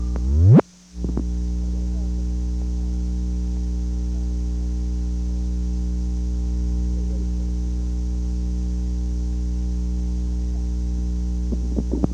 • White House operator
Location: White House Telephone
The President talked with the White House operator.